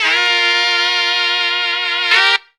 LONG CHORD.wav